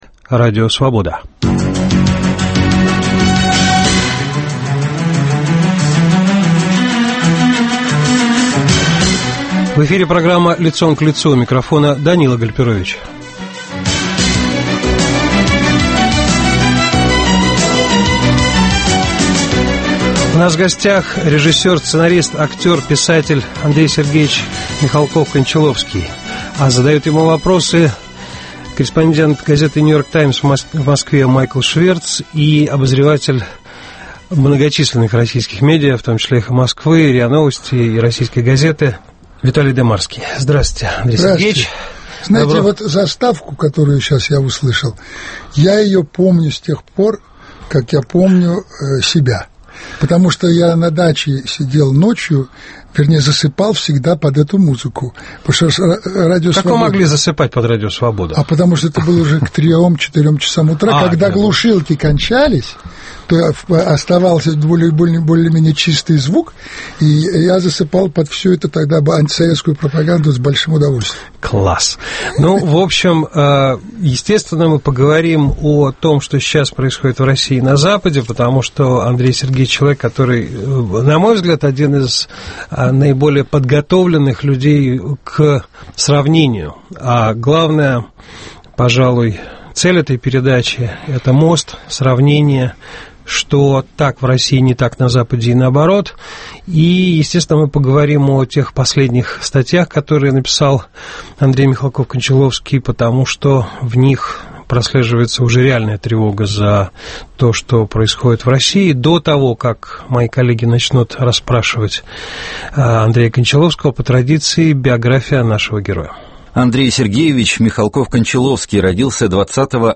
В программе - режиссер Андрей Кончаловский.